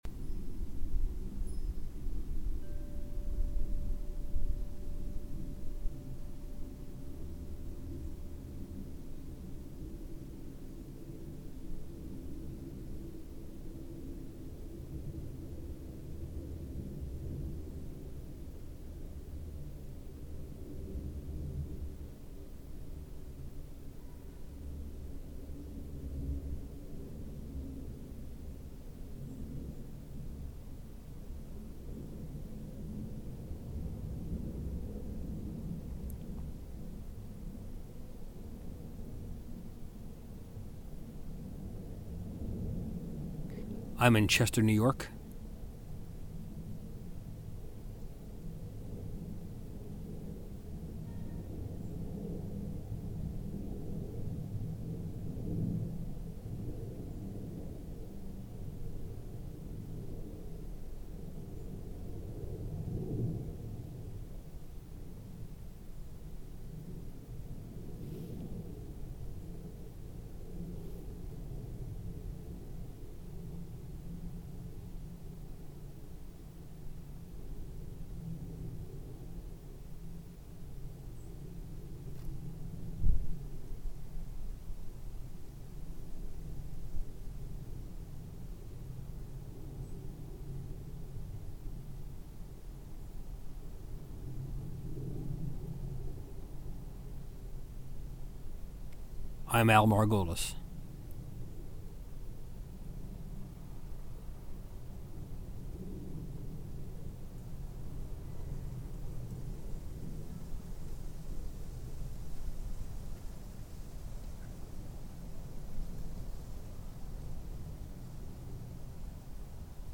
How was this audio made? In response to the invitation, people are sending in recordings from all over the world- they trickle in as the path of the sunrise of sunset moves around the globe. at home Chester, NY